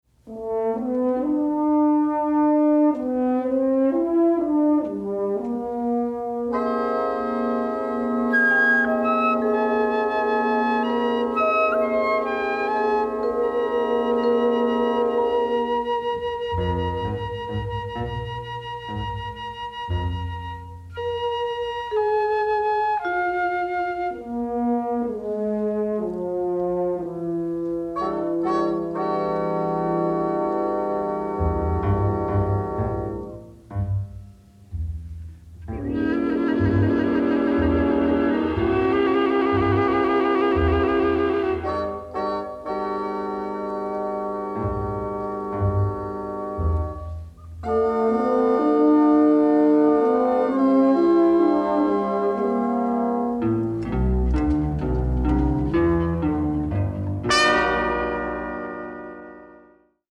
jazz-infused roller-coaster of a score